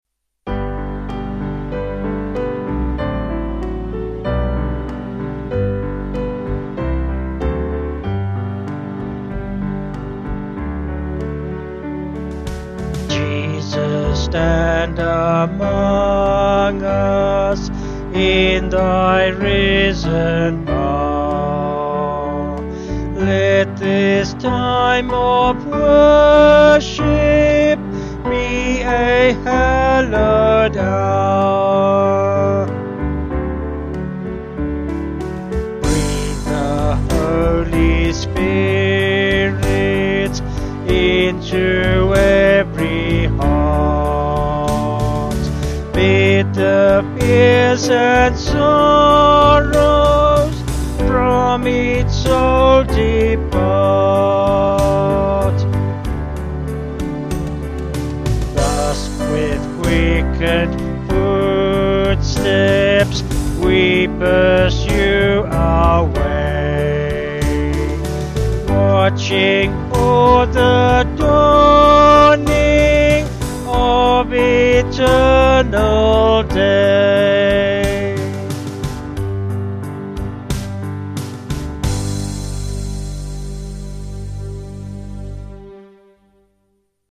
Vocals and Band   224.5kb